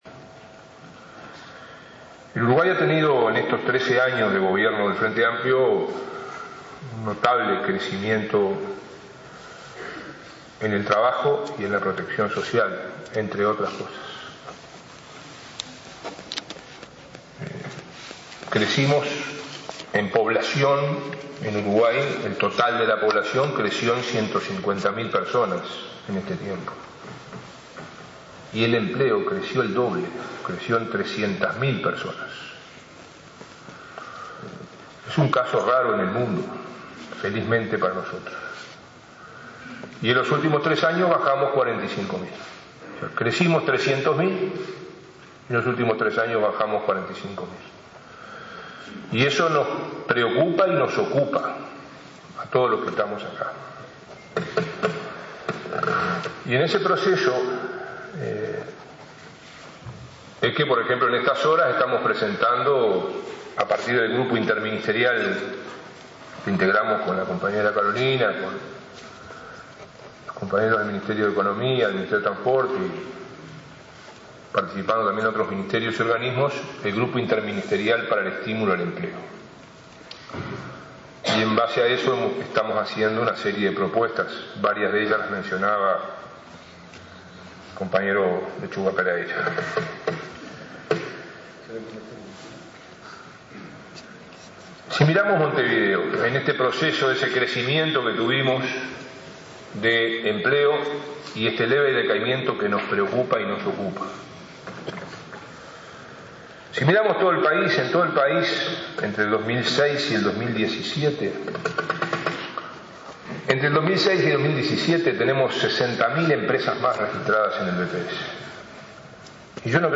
Durante un seminario sobre trabajo y empleo, que se realizó este martes en la intendencia capitalina, dijo que hay 30.000 empresas más registradas en BPS con domicilio en Montevideo.